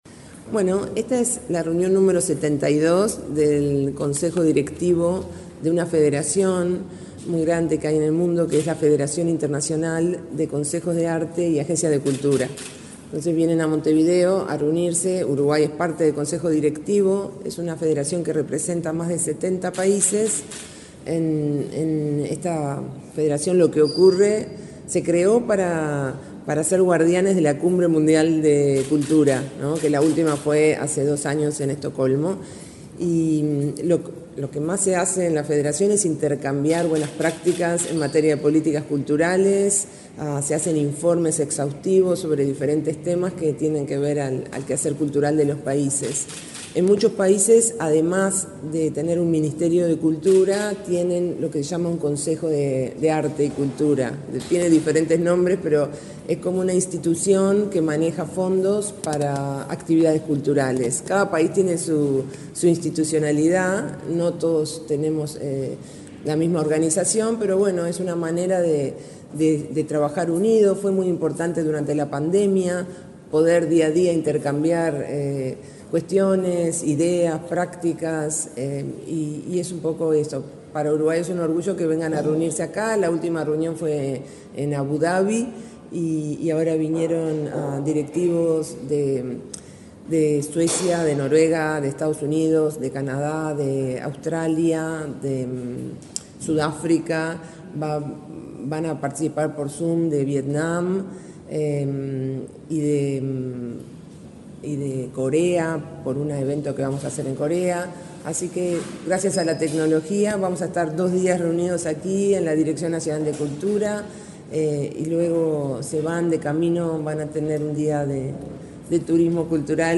Entrevista a la directora nacional de Cultura, Mariana Wainstein
La directora nacional de Cultura, Mariana Wainstein, dialogó con Comunicación Presidencial, este martes 27 en Montevideo, antes de la apertura de la 72.ª reunión de la Junta Directiva de la Federación Internacional de los Consejos de las Artes y Agencias Culturales. La jerarca integra este ámbito en el que Uruguay participa de forma activa junto con más de 70 países.